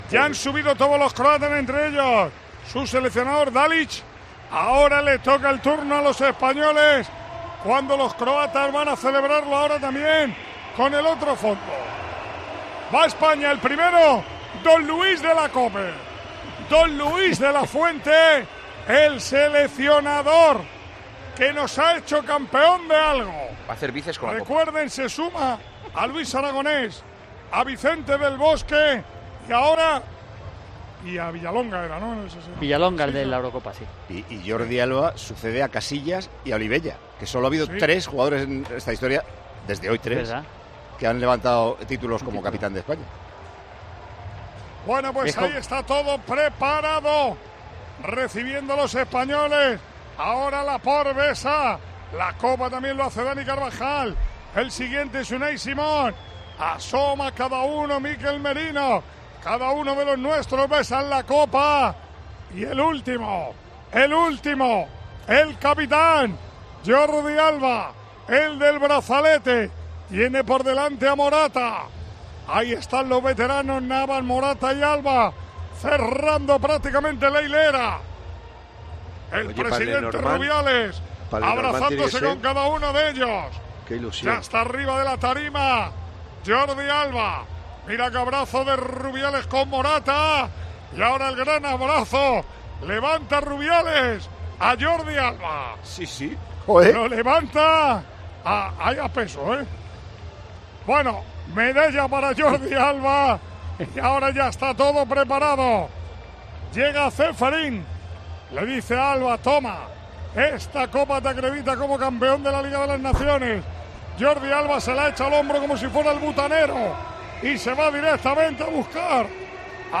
Así narramos la entrega de la Liga de las Naciones a la selección española